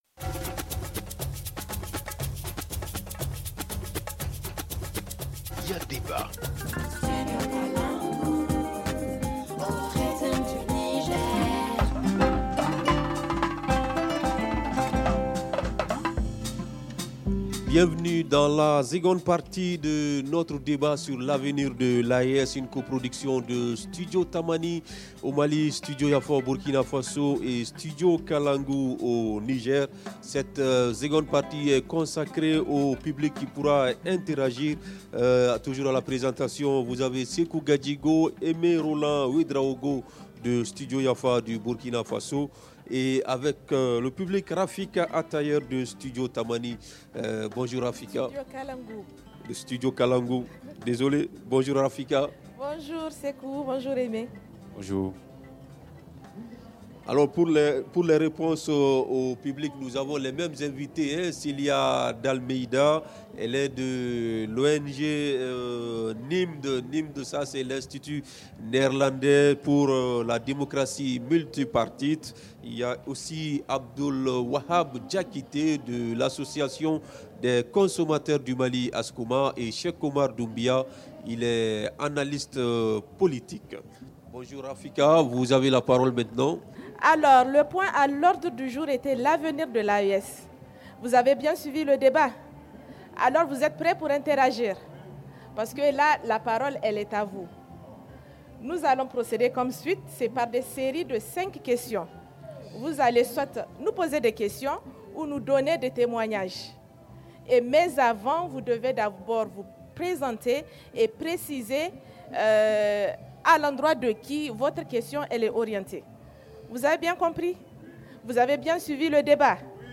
Studio Kalangou, Studio Yafa et Studio Tamani posent le débat ici sur le site du festival Ogobagna.